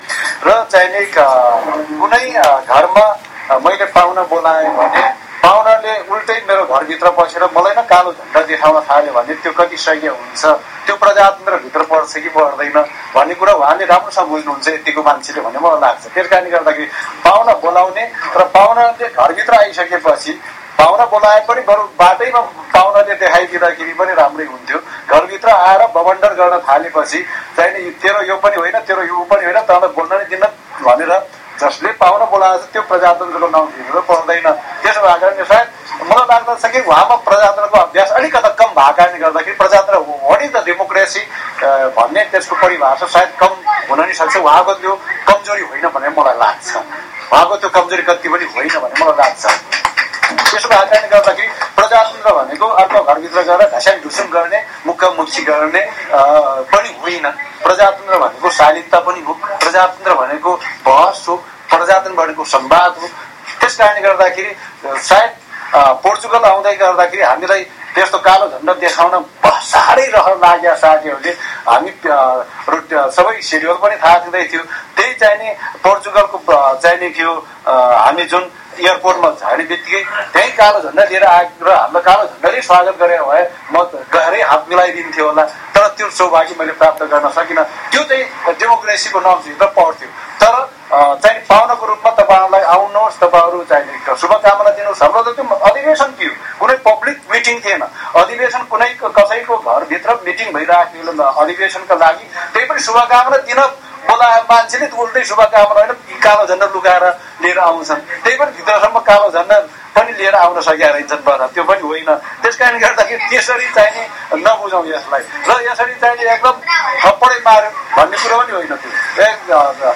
नेपाली जनसम्पर्क समिति स्पेनले बार्सिलोनामा आयोजनामा गरेको कार्यक्रममा पोर्तुगलको झपड बारे प्रसंग चले पछि उनले यस्तो बताएका हुन्।
Dhan Raj Gurung voice